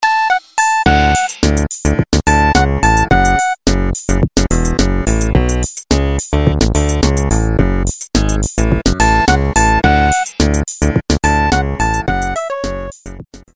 Nokia полифония. Зарубежные